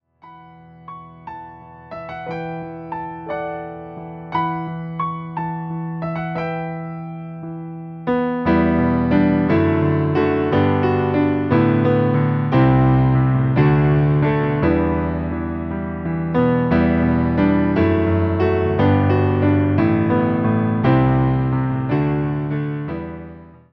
58 BPM
e – moll